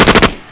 Gun1
GUN1.WAV